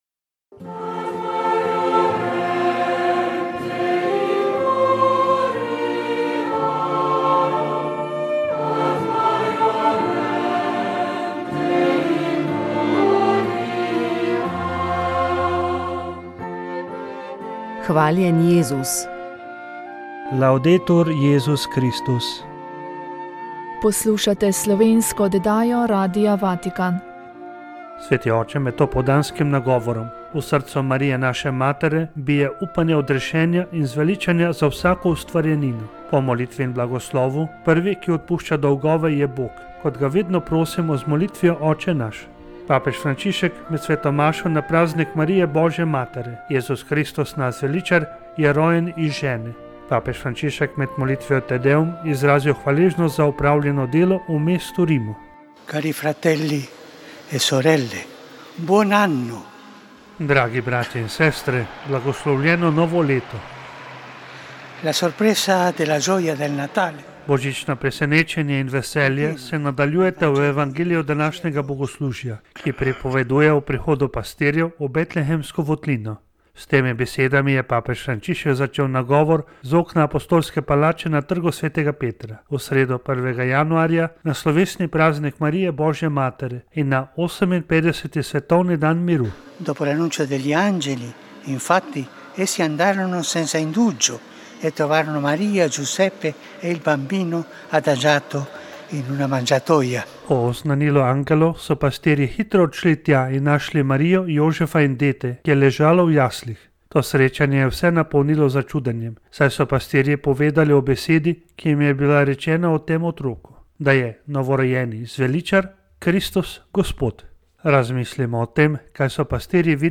O knjigi in p. Corteseju so na predstavitvi v Ljubljani